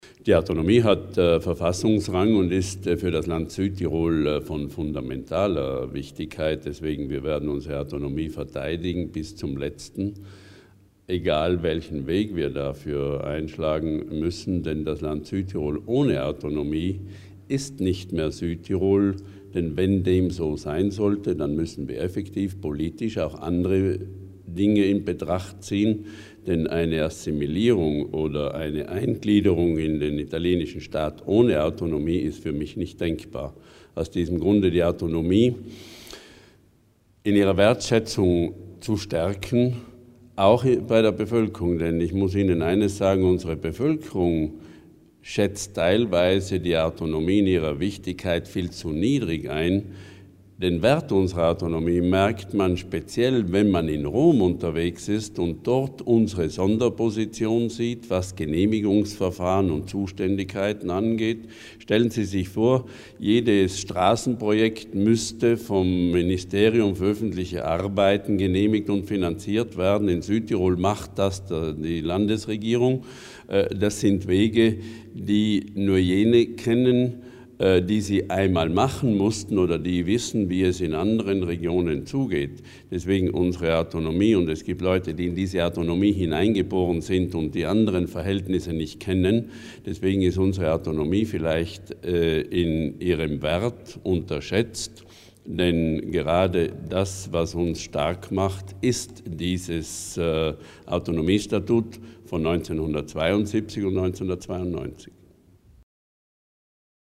Landeshauptmann Durnwalder über die Bedeutung der Auszeichnung von Fischer und Napolitano